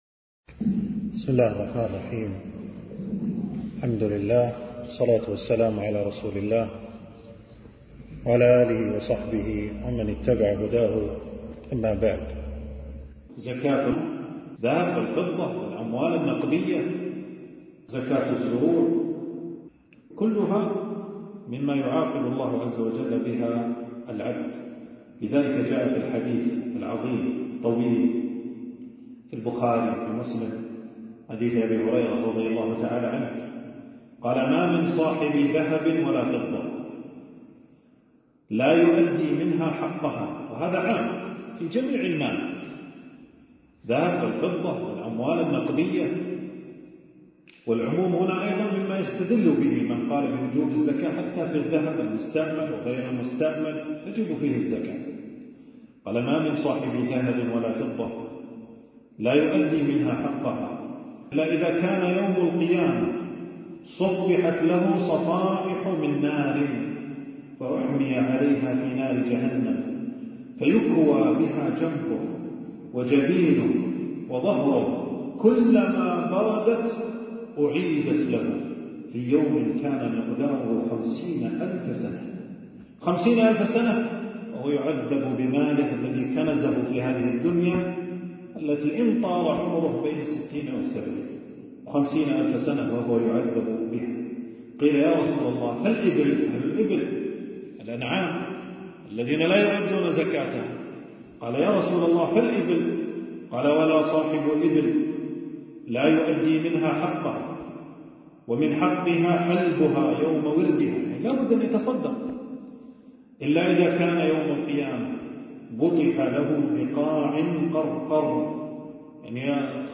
تنزيل تنزيل التفريغ محاضرة بعنوان: أحكام الزكاة.
في مسجد مالك بن الحويرث - بمدينة المطلاع.